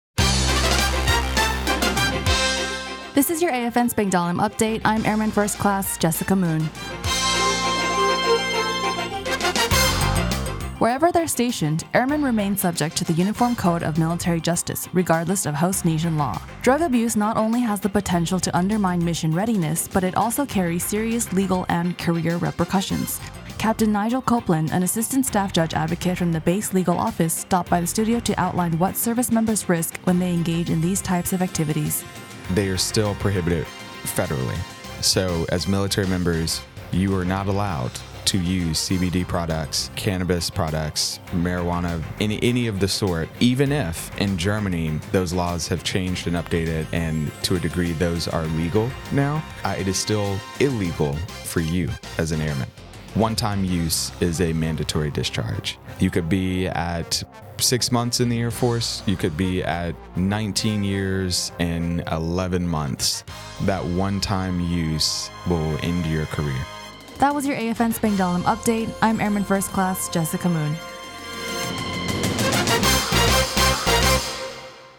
The following was the radio news report for AFN...